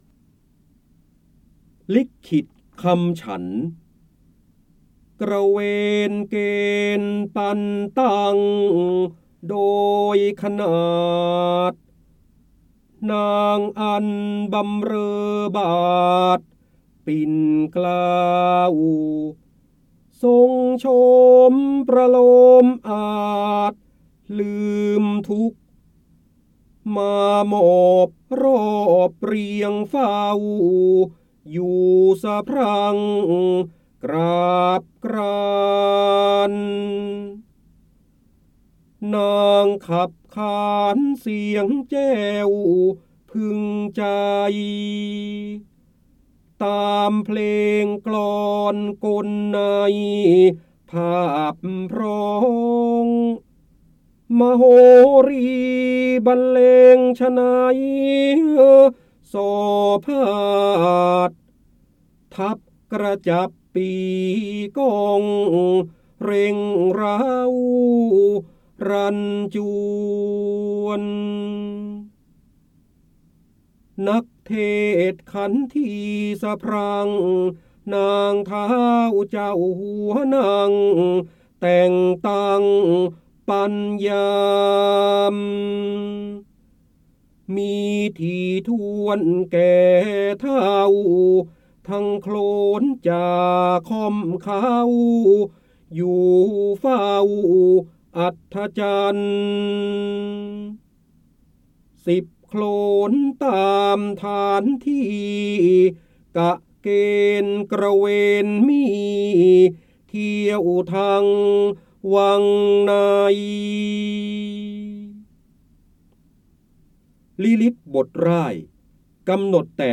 เสียงบรรยายจากหนังสือ จินดามณี (พระโหราธิบดี) ลิกขิตคำฉันท์
คำสำคัญ : ร้อยแก้ว, พระเจ้าบรมโกศ, ร้อยกรอง, จินดามณี, การอ่านออกเสียง, พระโหราธิบดี